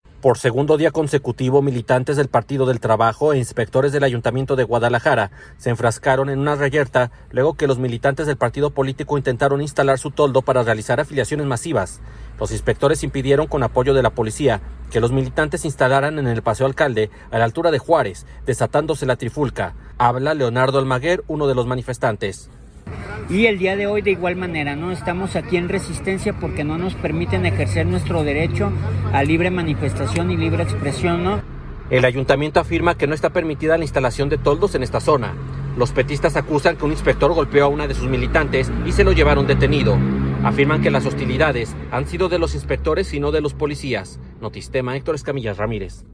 uno de los manifestantes